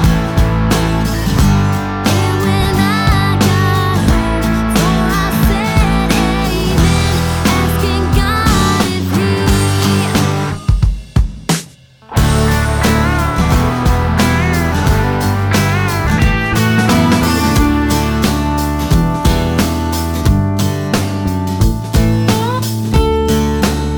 For Male Female Duet Pop (2010s) 3:22 Buy £1.50